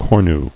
[cor·nu]